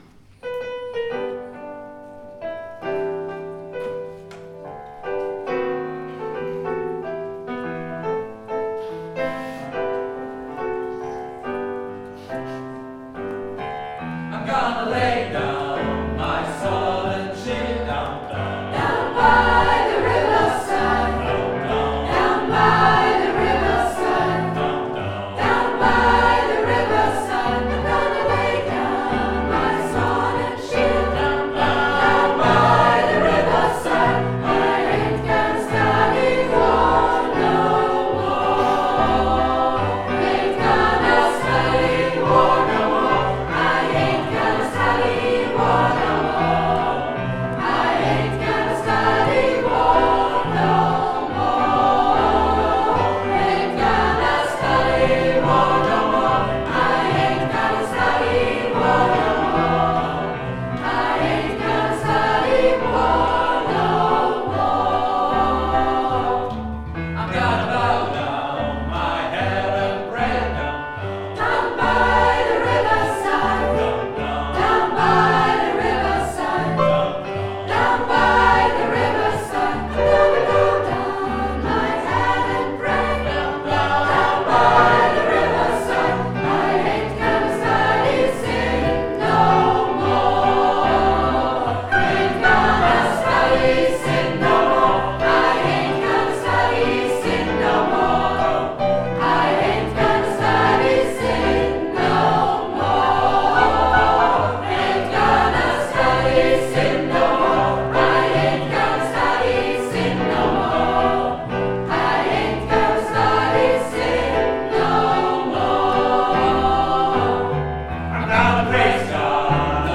Gospelchor der Gnadenkirche Wien
Down by the riverside - Aufnahme aus der Thomaskirche, April 2014 (4,88 mb)